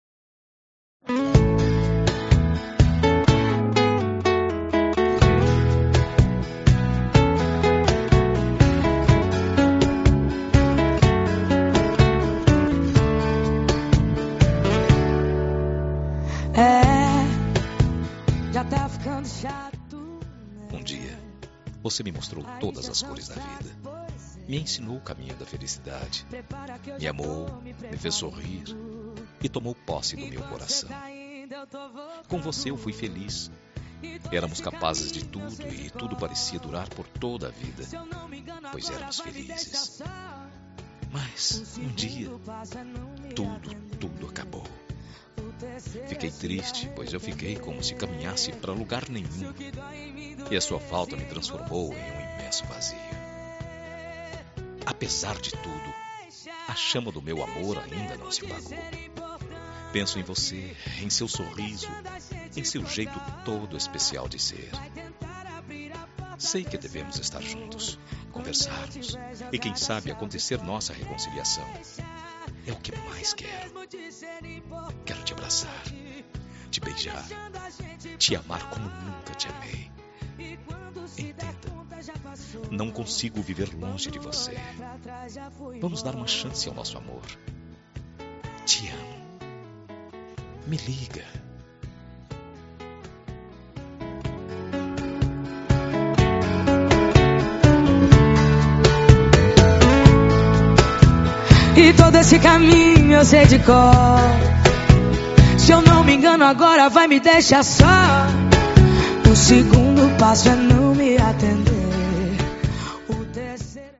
Telemensagem de Reconciliação – Voz Masculina – Cód: 7555